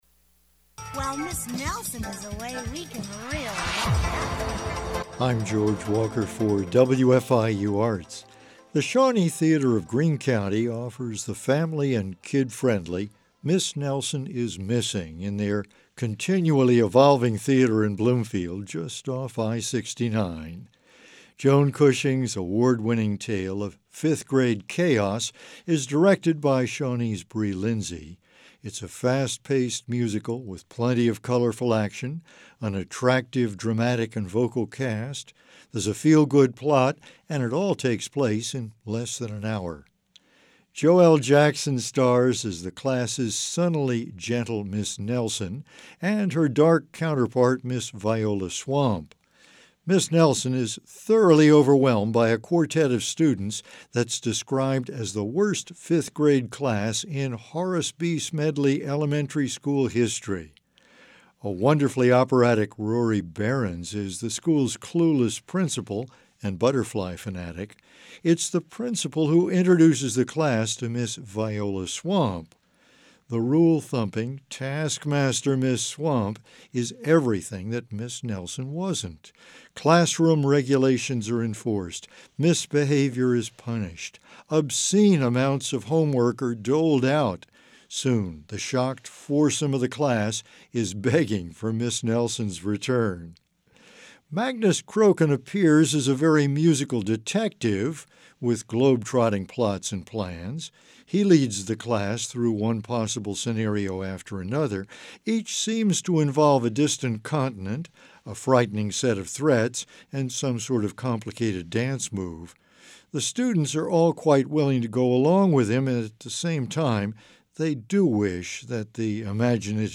It's a fast paced musical with plenty of colorful action, an attractive dramatic and vocal cast.
At the beginning of the show and at the end, they don choir robes and do a lovely job of part singing with the school's anthem.